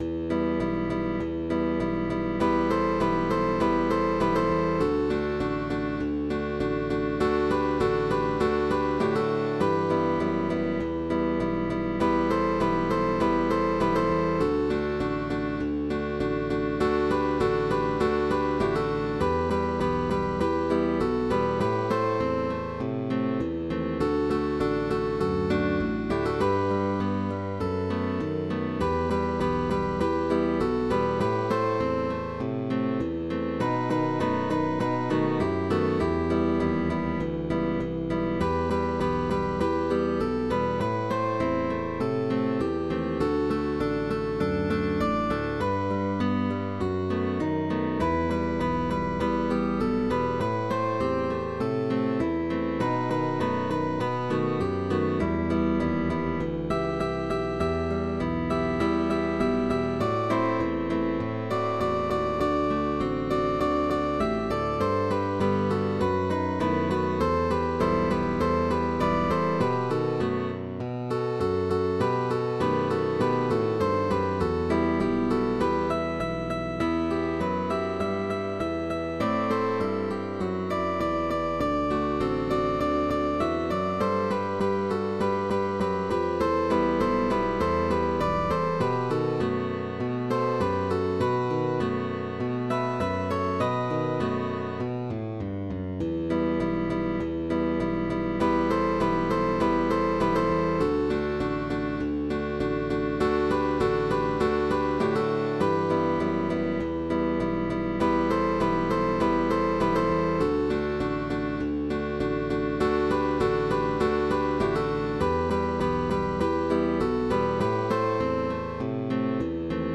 GUITAR TRIO
FREE Score by Guitar trio with optional bass.
Pop Songs